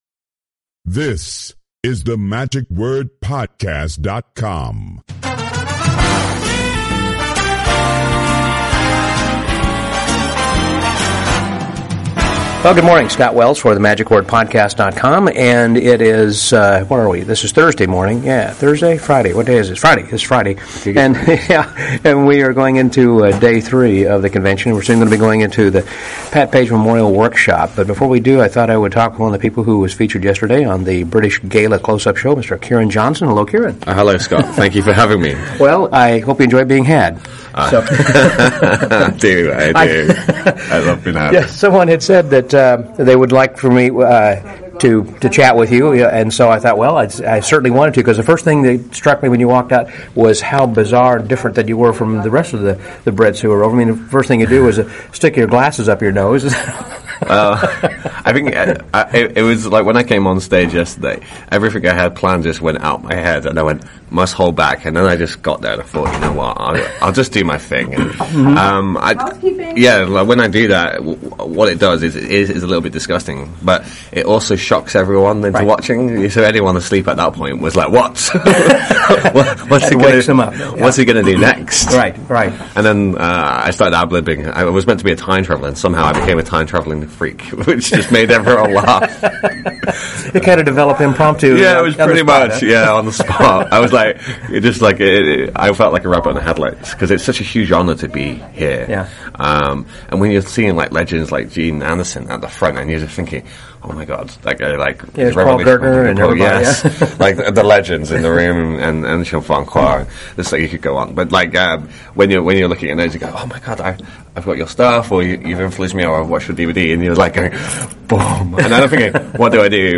Day Three with even more great shows and more great chats with some of the attendees.